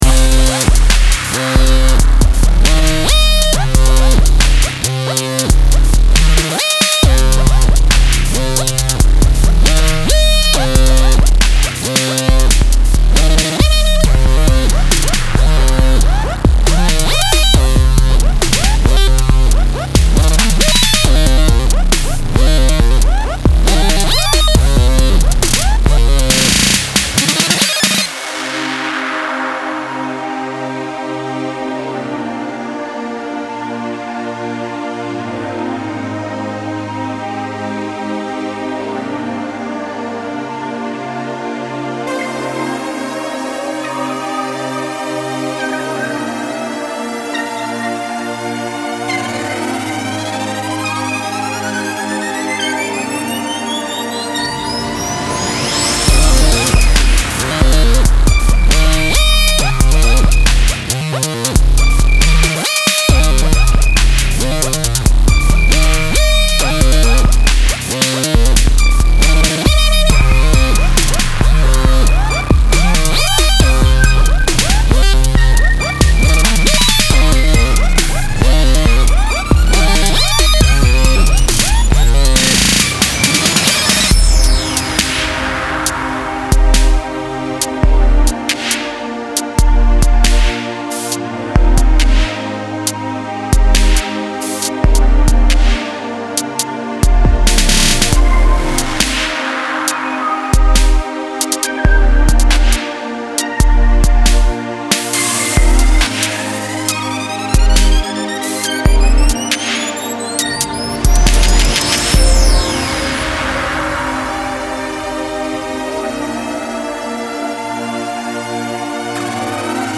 Style Style EDM/Electronic, Hip-Hop
Mood Mood Driving, Intense
Featured Featured Bass, Drums, Synth
BPM BPM 137